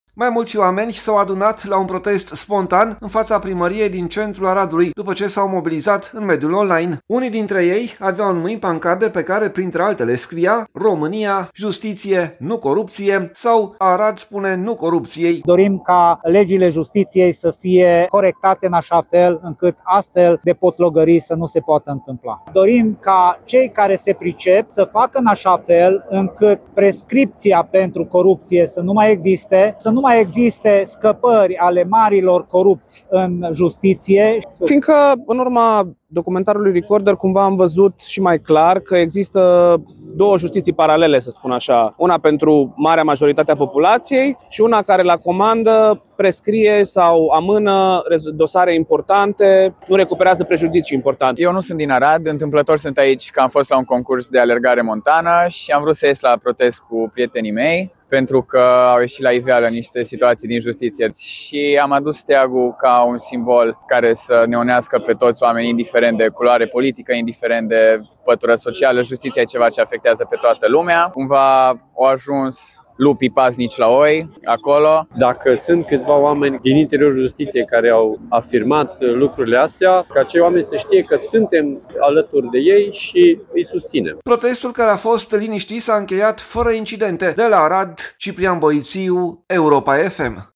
Pentru prima dată de la începerea protestelor, sâmbătă seara oamenii au ieșit în stradă și în Arad.
Mai mulți oameni s-au adunat la un protest spontan, în fața primăriei, din centrul orașului, după ce s-au mobilizat în mediul online.
Protestul, care a fost liniștit, s-a încheiat fără incidente.